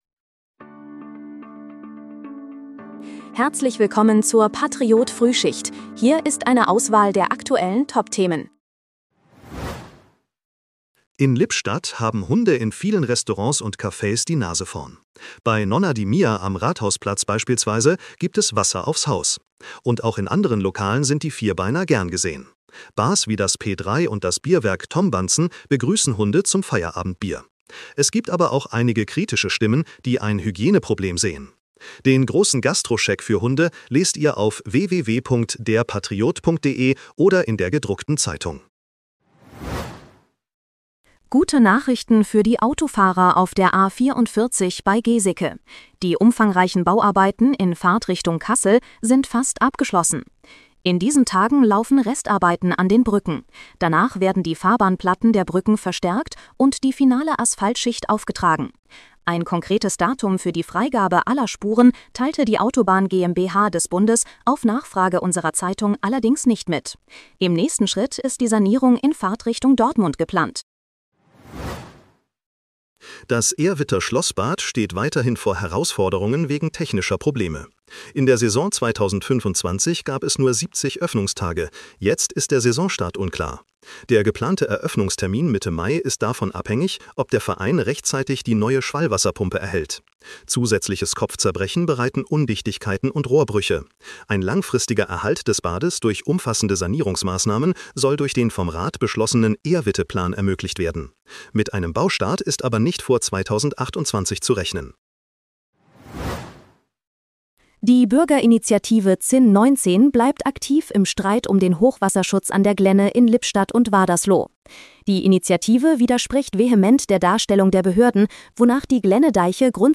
Willkommen zur Patriot-Frühschicht. Dein morgendliches News-Update
mit Hilfe von Künstlicher Intelligenz.